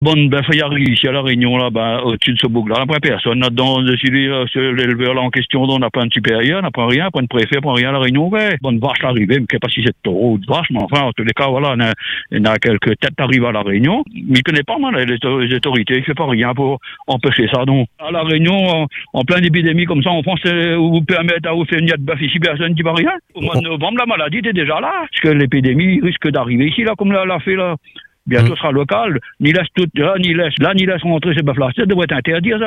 À l’image de cet auditeur, dont le témoignage illustre un sentiment largement partagé, nombreux sont ceux qui réclament plus de transparence, mais aussi des garanties strictes sur les contrôles effectués avant et après l’importation.